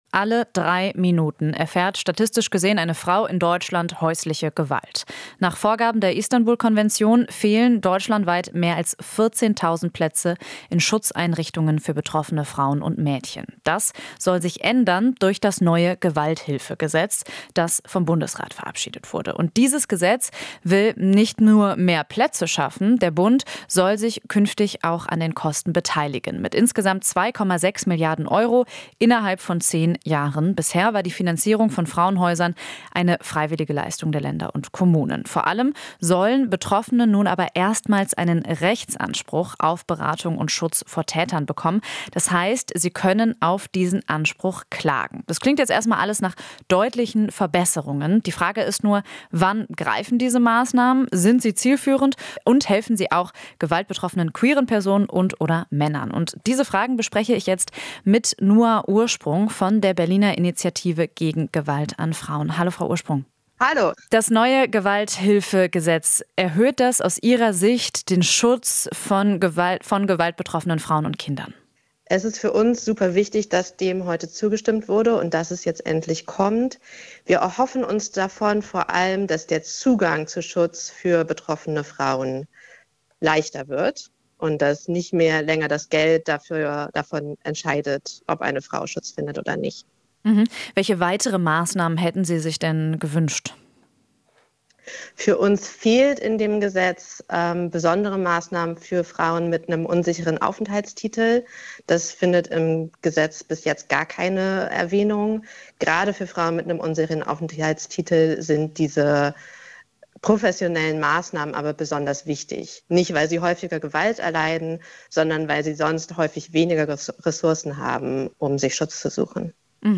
Interview - BIG: Haben seit über 20 Jahren für Gewalthilfegesetz gekämpft